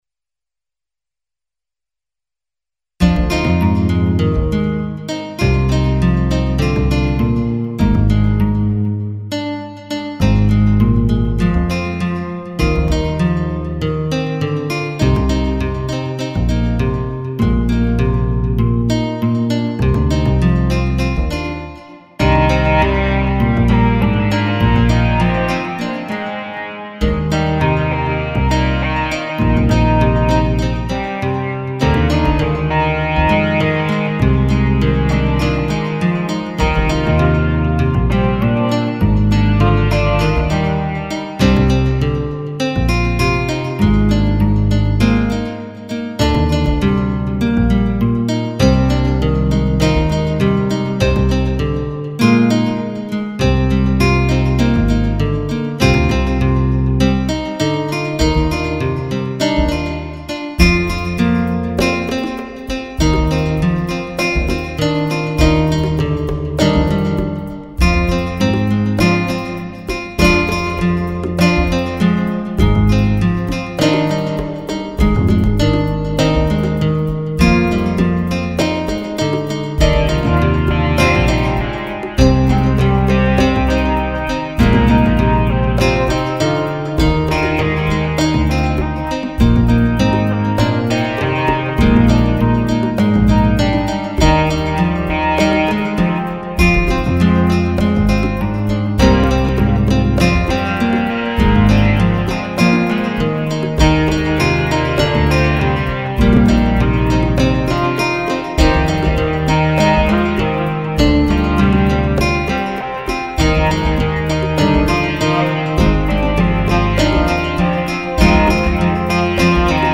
2 morceaux de guitare en 2 versions à partir du même algorithme - 1/f Noise : Ur pennad (Un moment) et Un tennad (Un coup).
1/f Noise (--/--) Sol (G) - Phrygien 100
Drum loop : Arythm